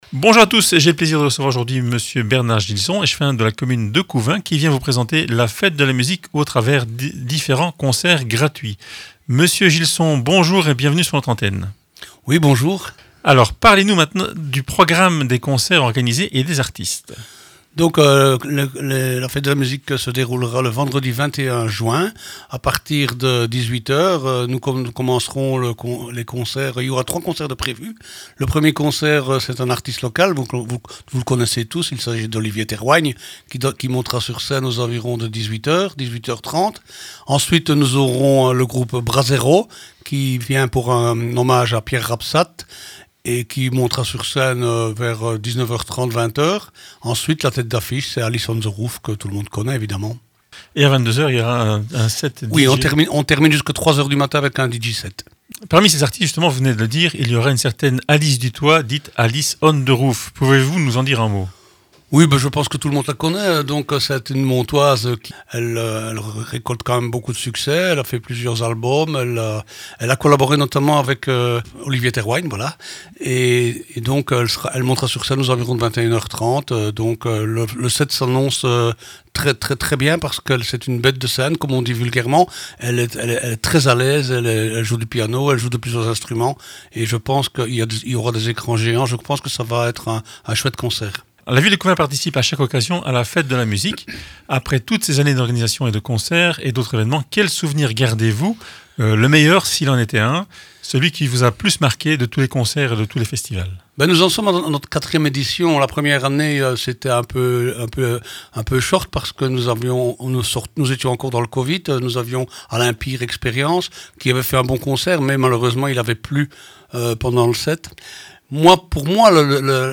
Mr Bernard GILSON, échevin de la commune de Couvin, présente le programme de la fête de la musique 2024 à COUVIN